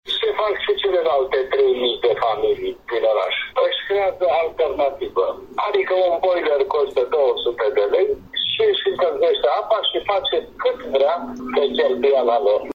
Primarul Leca Băncilă: